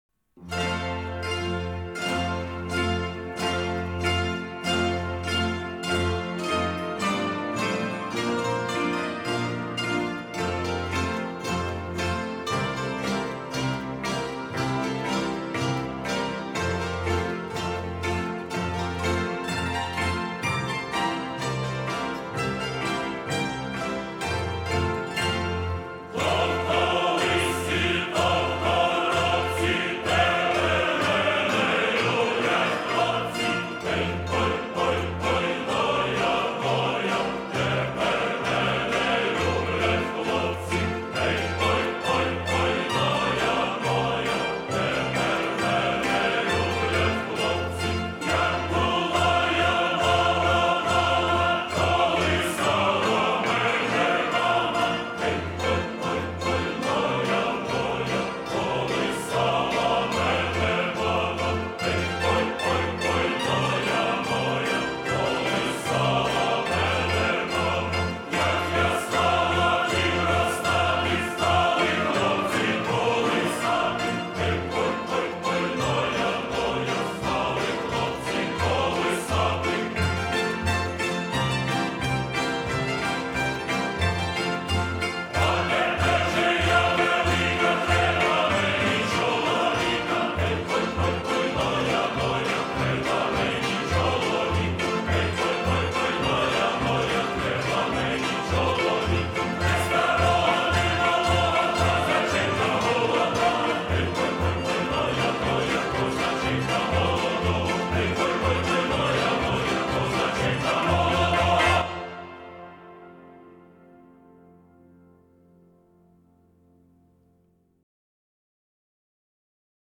Попробуем эту, еще одну старинную украинскую песню, в качестве колыбельной. И словами прямо напрашивается, и музыка укачивает!